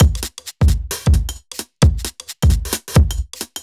Index of /musicradar/uk-garage-samples/132bpm Lines n Loops/Beats